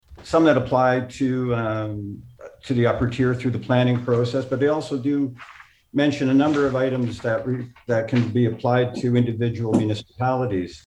Mayor Jenkins made this point during a meeting of the Hastings County Planning committee this week.